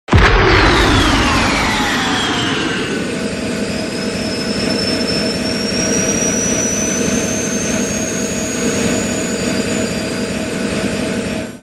9. Супергеройская скорость. Резкий набор скорости